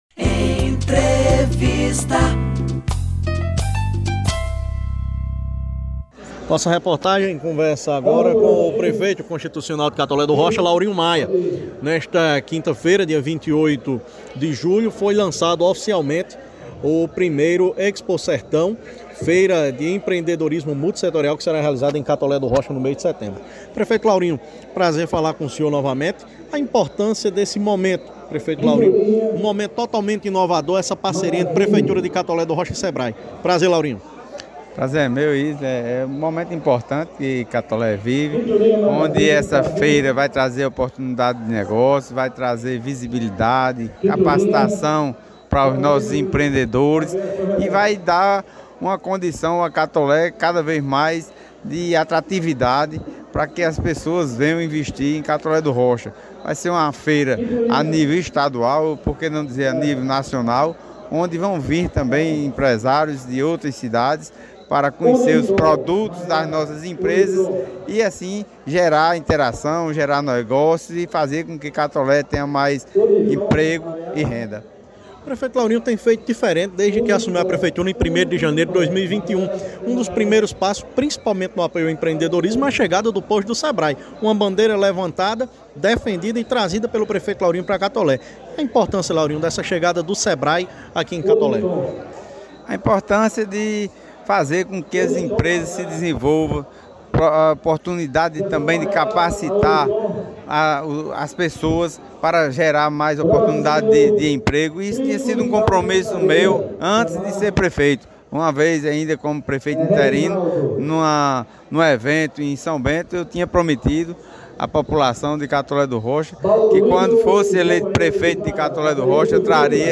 Entrevista-Prefeito-Laurinho-Maia-Expo-Sertao-2022.mp3